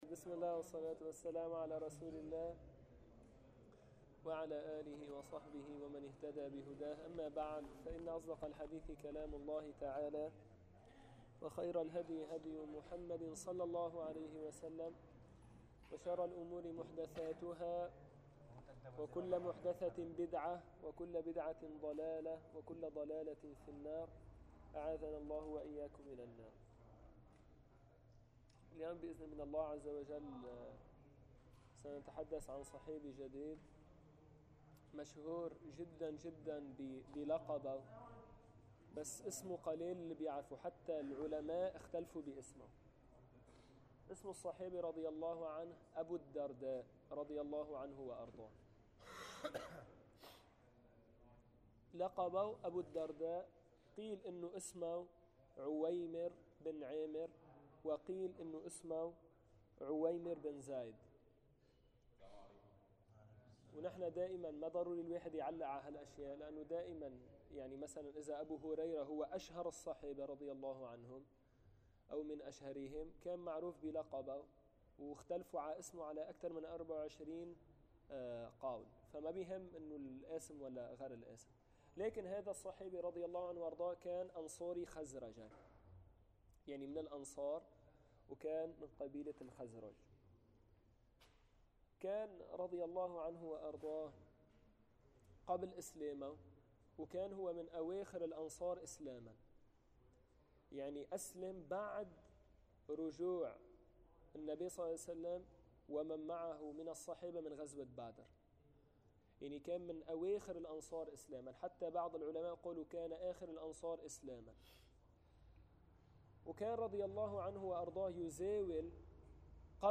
من دروس مسجد القلمون الغربي الشرعية